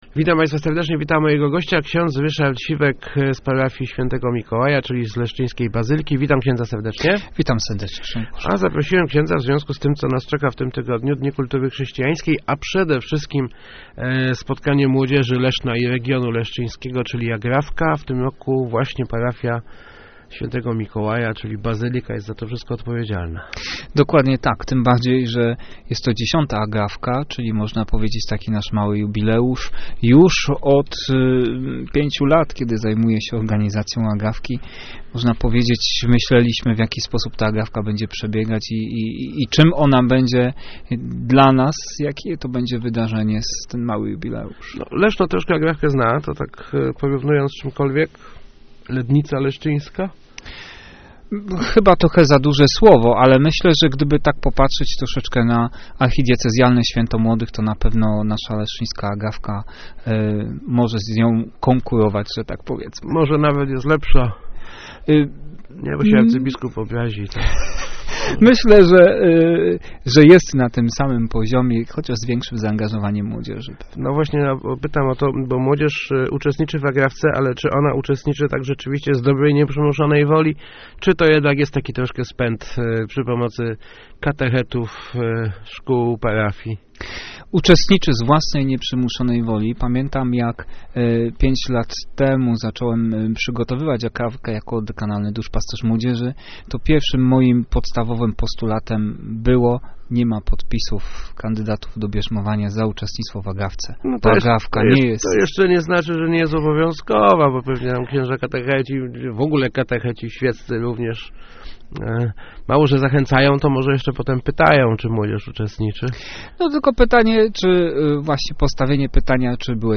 Rozmowach Elki